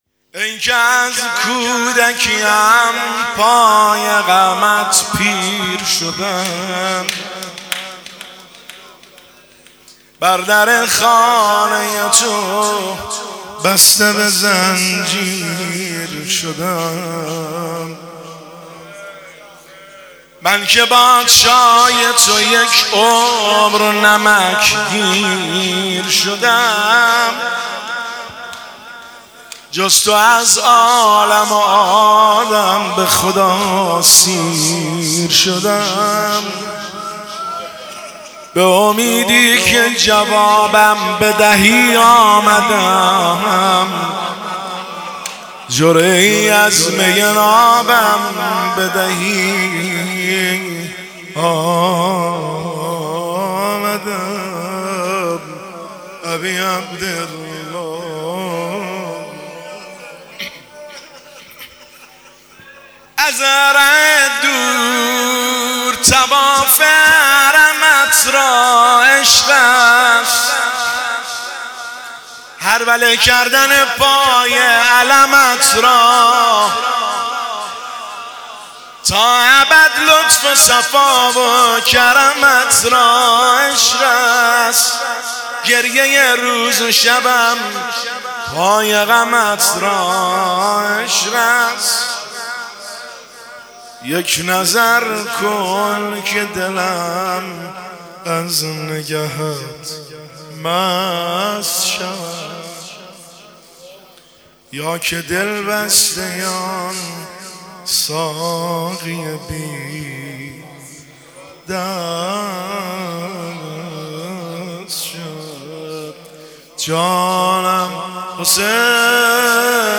دانلود سبک سنگین